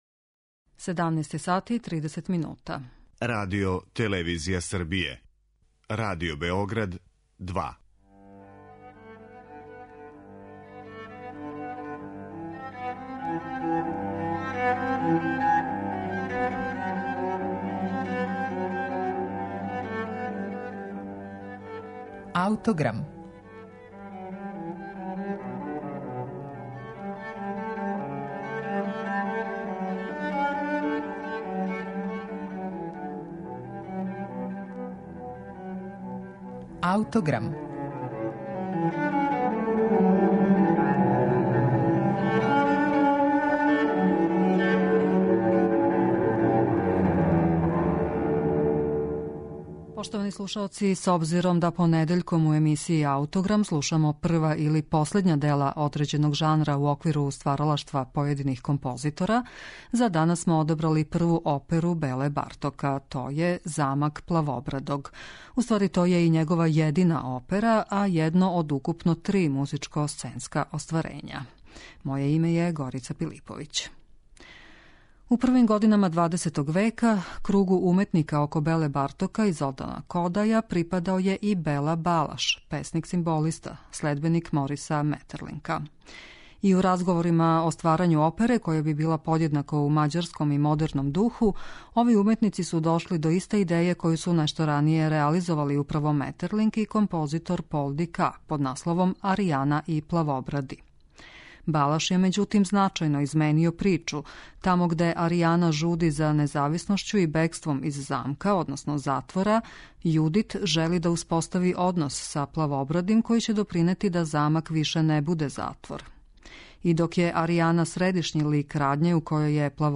оперу